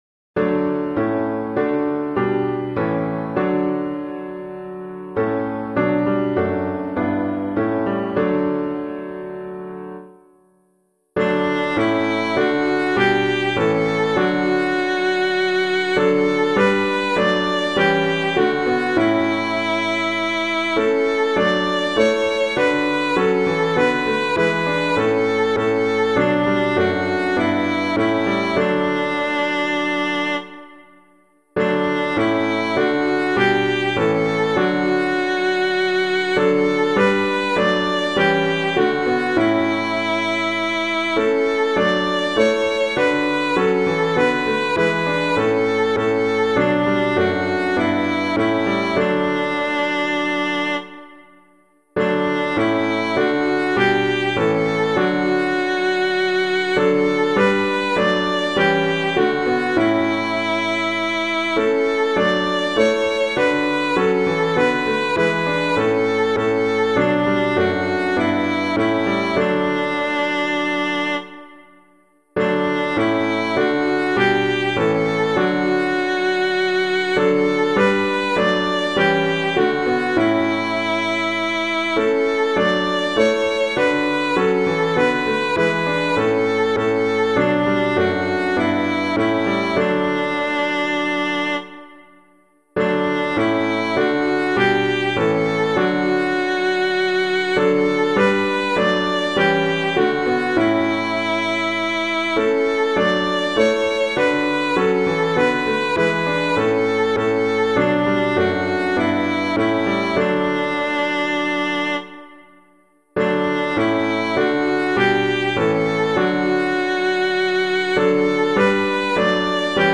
piano
The Coming of Our God [Campbell - FRANCONIA] - piano.mp3